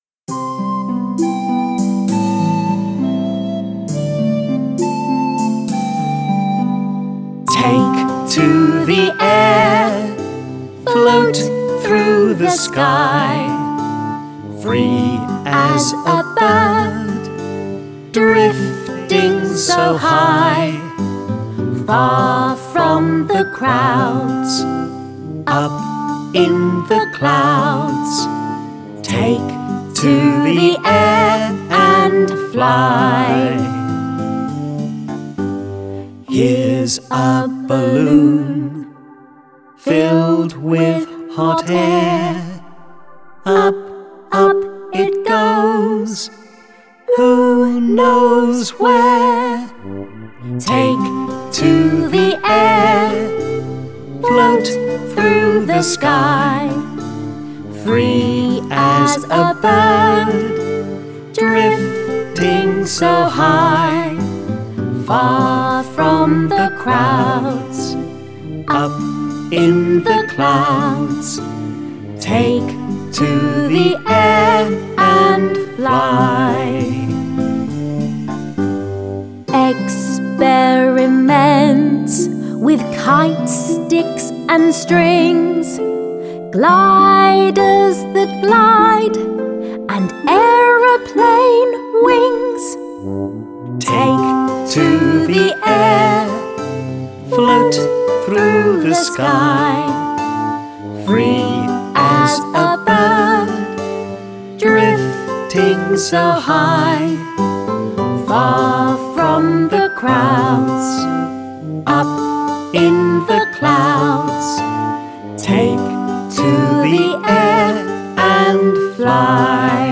Guide vocal